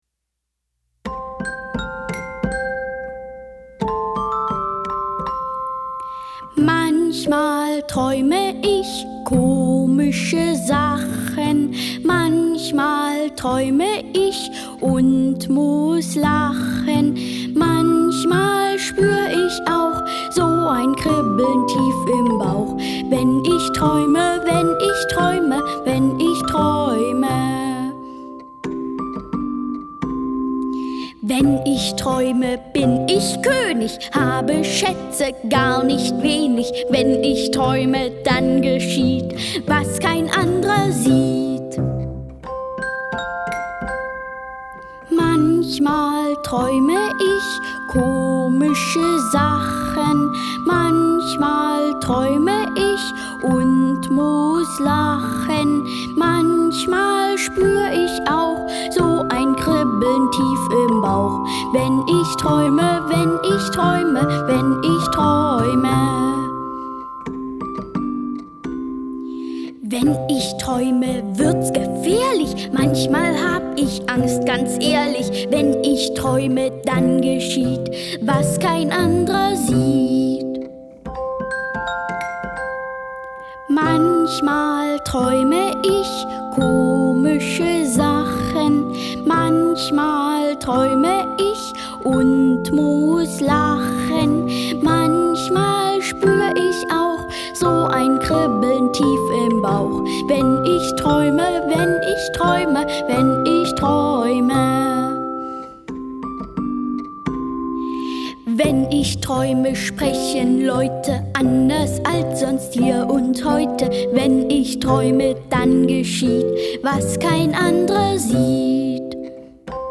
Ulrich Noethen, Katharina Thalbach (Sprecher)
Auf dieser CD lesen Dir Katharina Thalbach und Ulrich Noethen vier Geschichten vom Volk Israel in Ägypten vor: "Josef und seine Brüder", "Der kleine Mose", "Mose und der brennende Dornbusch" und "Der Auszug aus Ägypten". Mit Liedern zum Nachdenken und Mitmachen.